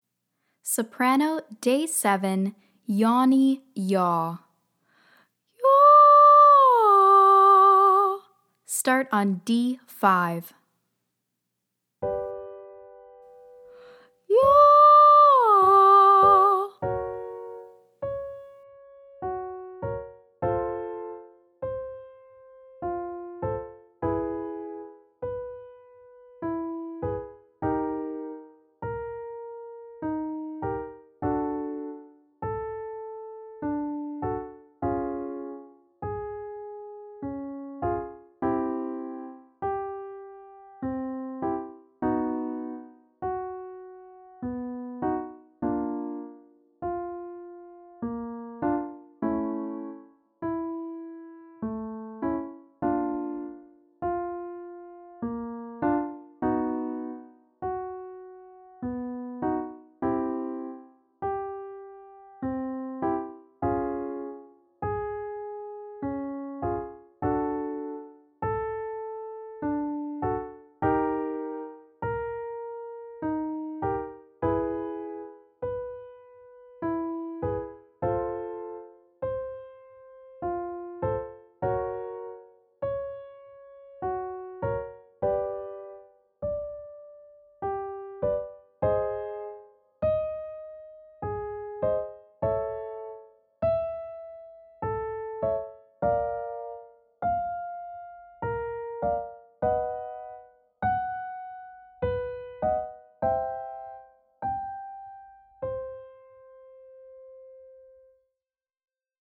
Day 7 - Soprano - Yawny YAH